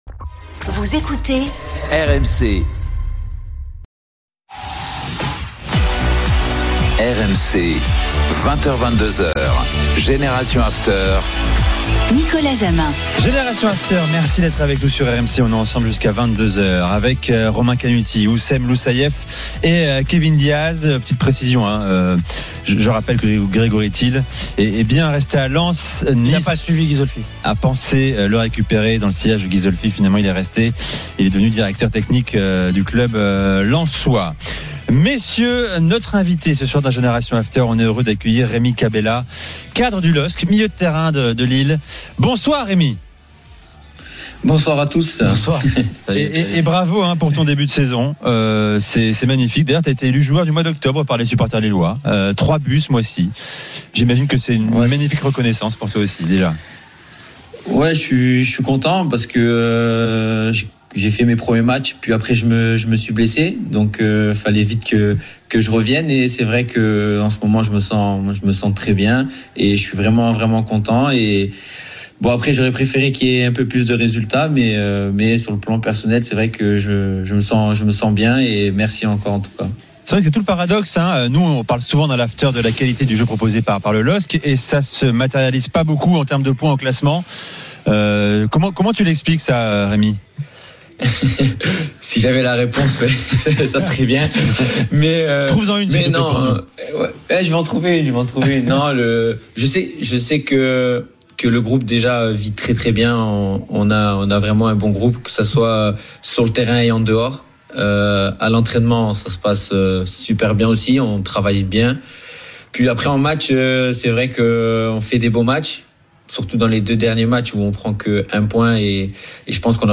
Le Top de Génération After : Rémy Cabella invité de Génération After – 10/11